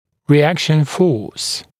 [rɪ’ækʃn fɔːs][ри’экшн фо:с]противодействующая сила